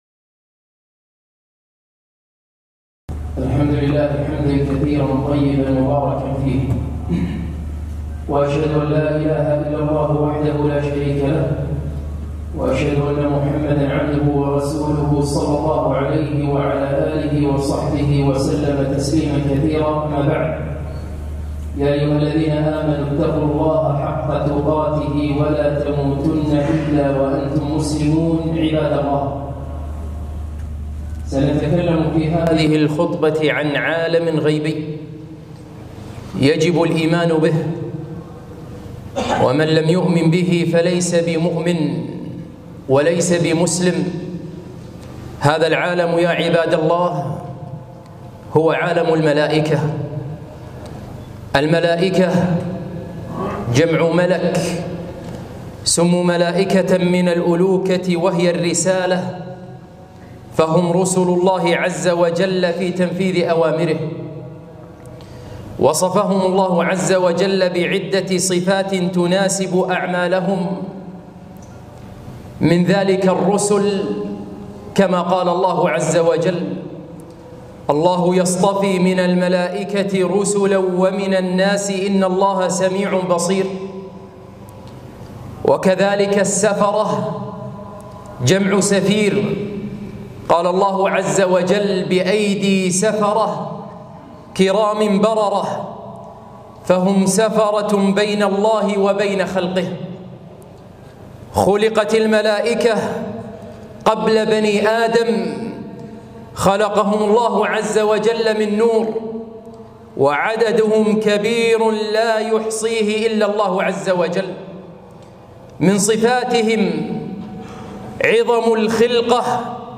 خطبة - أسرار لا تعرفها عن الملائكة